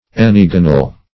Search Result for " enneagonal" : The Collaborative International Dictionary of English v.0.48: Enneagonal \En`ne*ag"o*nal\, a. (Geom.) Belonging to an enneagon; having nine angles.